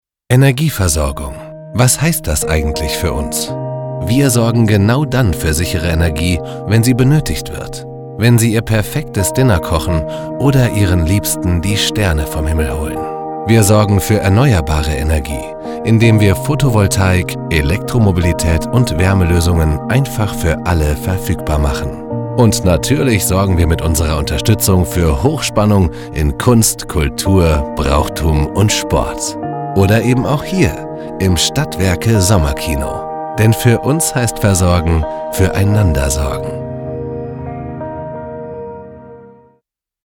Male
Approachable, Assured, Authoritative, Bubbly, Character, Confident, Conversational, Cool, Corporate, Deep, Energetic, Engaging, Friendly, Funny, Gravitas, Natural, Posh, Reassuring, Sarcastic, Smooth, Soft, Streetwise, Upbeat, Versatile, Wacky, Warm, Witty
Commercial Synchron.mp3
Microphone: Tlm 103, SA87, Aston Spirit